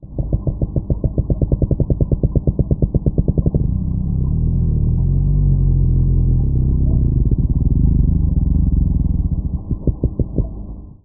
05FishingToday_HaddockCall.mp3